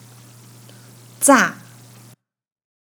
ヂャ
zhà